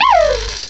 cry_not_shinx.aif